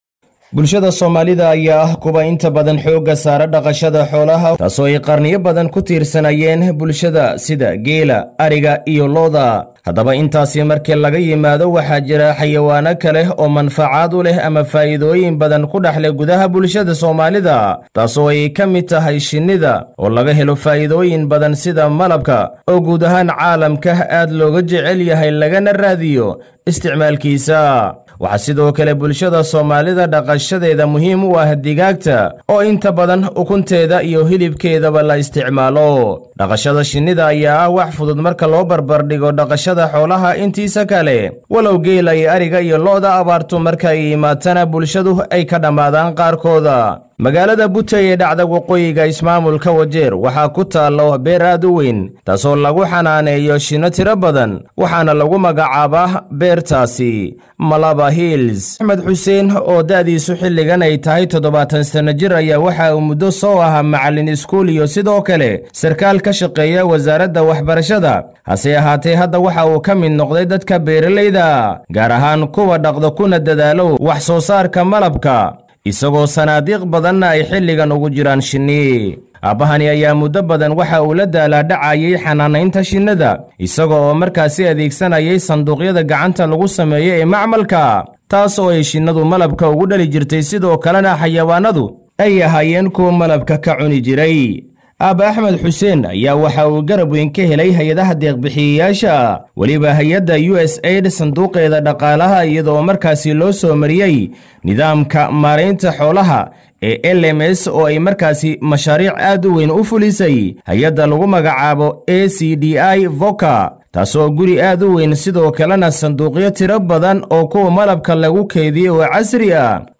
warbixintan ka soo diray ismaamulka Wajeer.